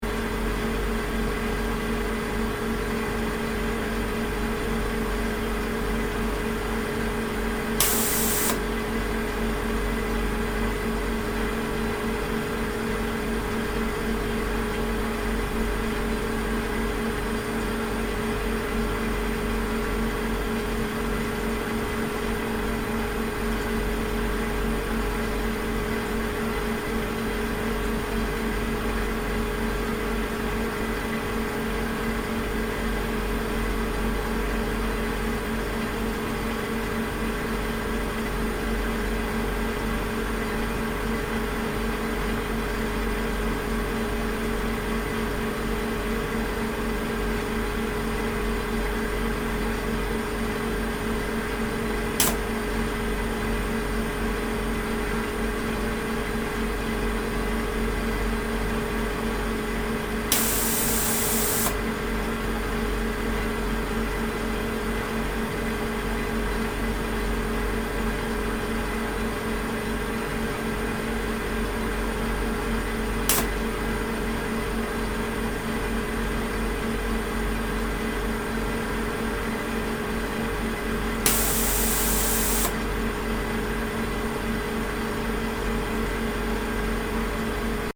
Soundscape: La Silla ESO 3.6-metre-telescope HARPS
Inside the High Accuracy Radial velocity Planet Searcher (HARPS) room, located on the third floor of the ESO 3.6-metre telescope building.
Soundscape Stereo (wav)